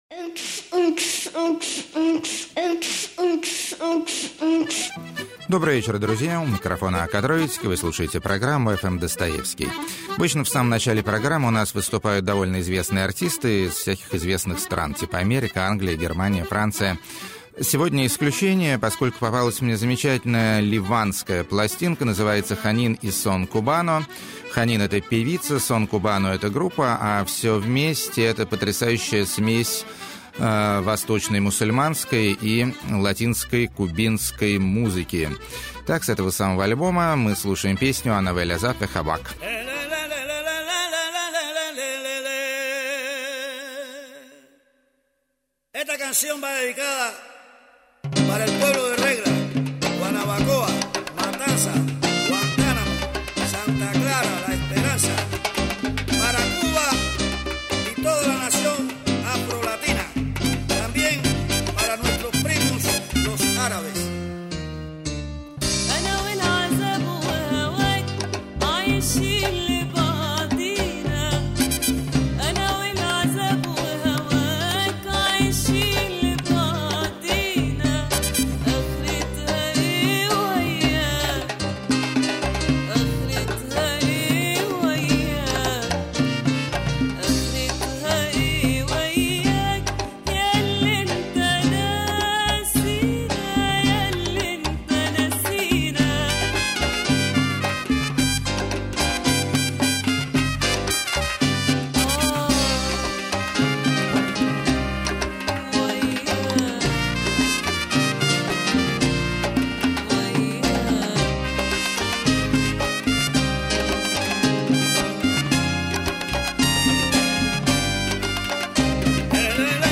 Арабо-кубинский Коктейль Удался!
Трип-хоп Жив.
Гитарный Психоделик А Ля 60-е.
Забавные Детские Песенки.] 14.
Нео-электро С Эпохального Сборника.